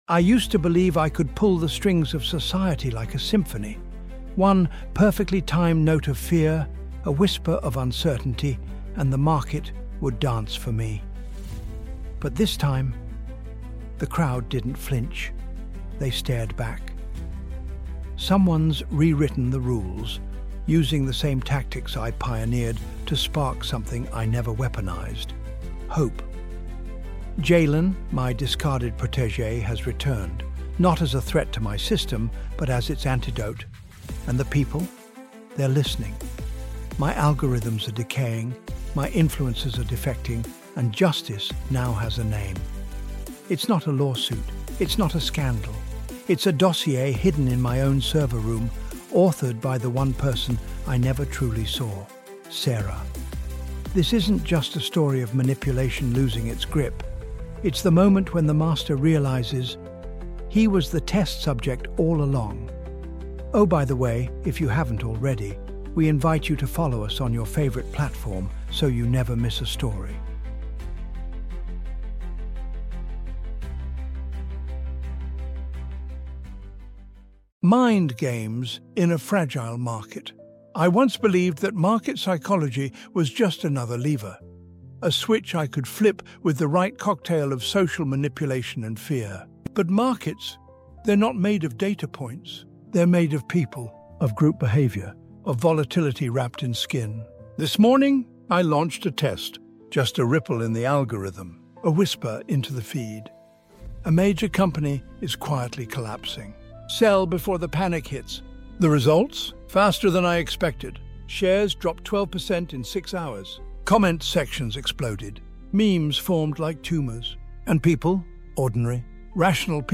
Inside the Mind of a Master Manipulator | Market of Minds | Audiobook